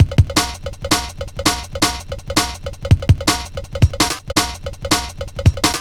Bull Bell B 01-165.wav